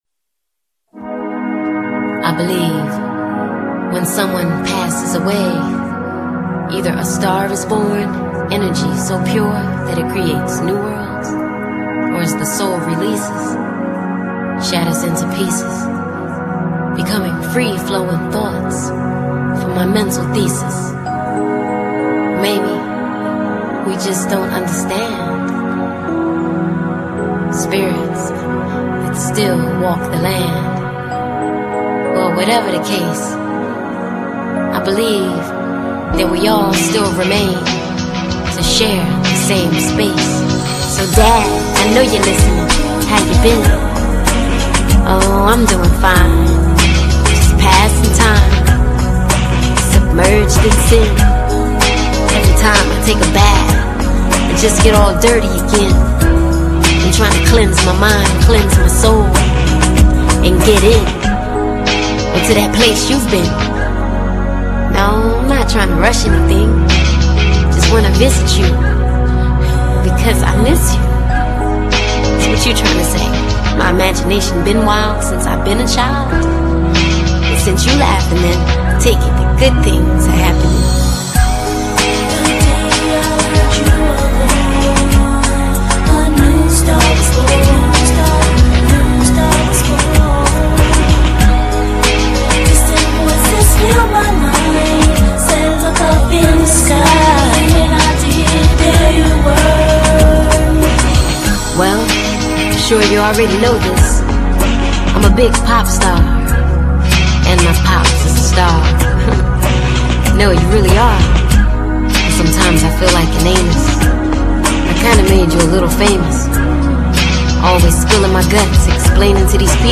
这张以饶舌、嘻哈与节奏蓝调曲风为主， 并点缀了电子、复古元素的新作